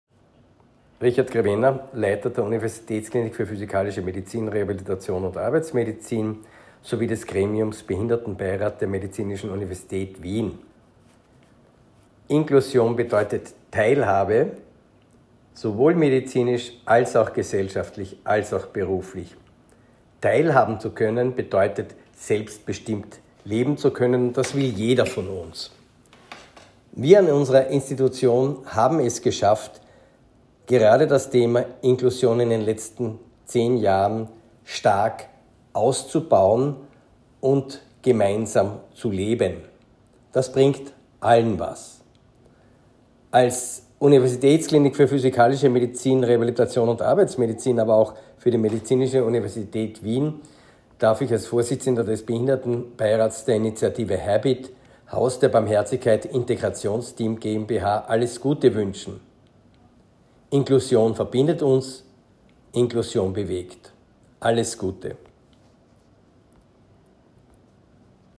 Bewegende Botschaften zum Thema Inklusion, gesprochen von Menschen aus Kunst, Kultur, Politik und Wissenschaft.